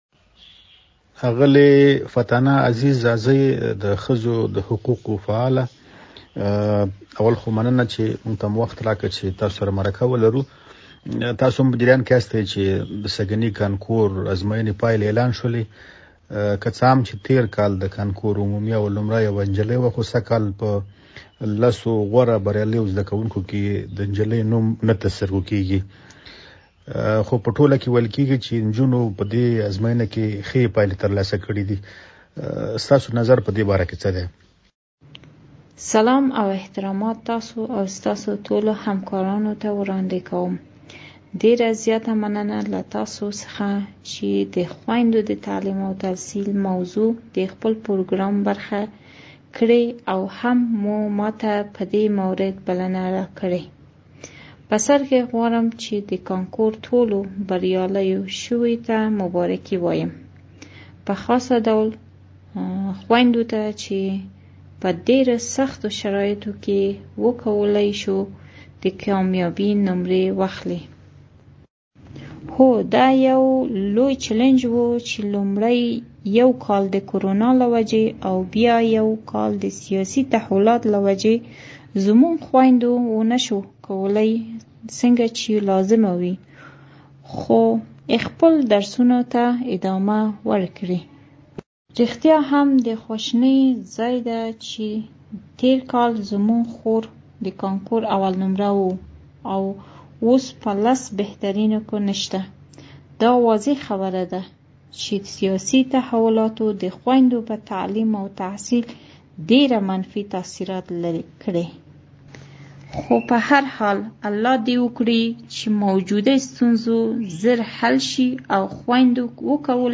بچوې: انجونی ، تعلیم ، افغانستان ، میرمنی ، طالبان ، مرکه ، هلکان ، سیالی ، کنکور